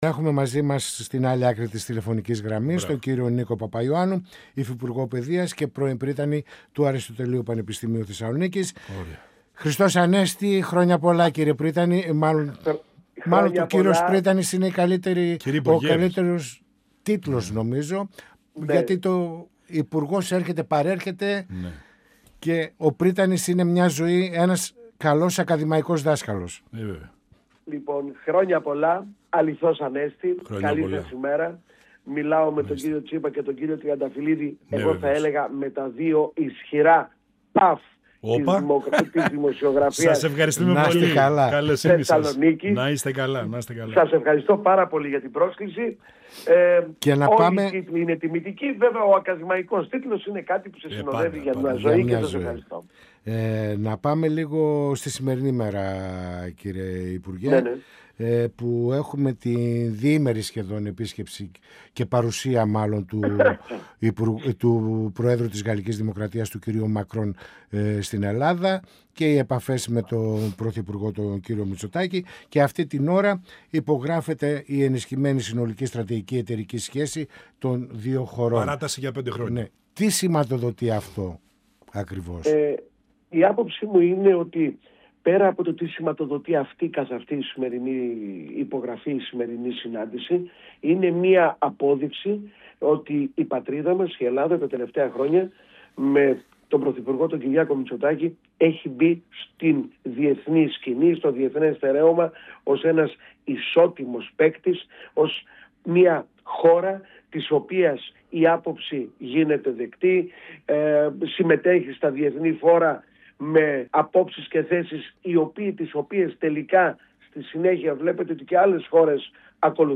Στις προτεραιότητες του κυβερνητικού έργου , την απόδοση των προσφάτων οικονομικών μέτρων της κυβέρνησης για την αντιμετώπιση των συνεπειών του πολέμου στη Μέση Ανατολή, από την εκτίναξη των τιμών του πετρελαίου, με στόχο την ενίσχυση και την ανακούφιση των ευάλωτων κοινωνικών ομάδων αναφέρθηκε ο Νίκος Παπαϊωάννου, Υφυπουργός Παιδείας, αρμόδιος για την Τριτοβάθμια Εκπαίδευση, μιλώντας στην εκπομπή «Πανόραμα Επικαιρότητας» του 102FM της ΕΡΤ3.
Συνεντεύξεις